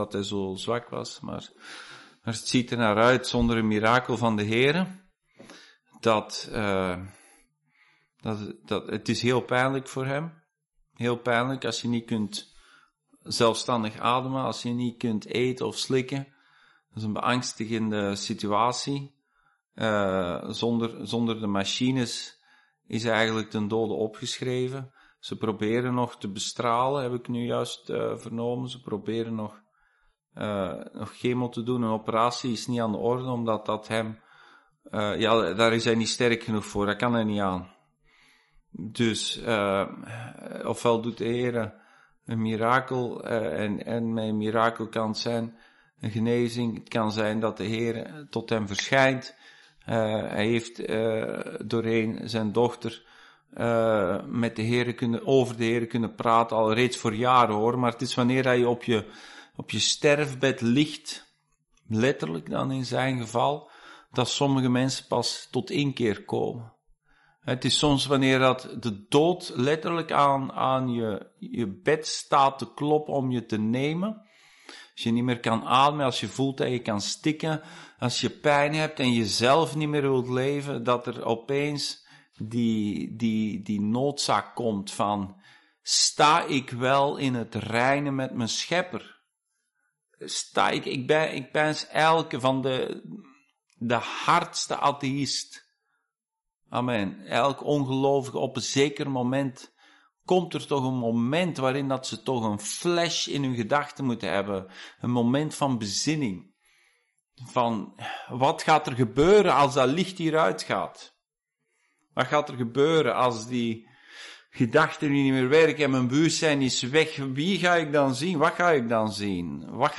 Hebreeën Dienstsoort: Bijbelstudie « Zoals het hart is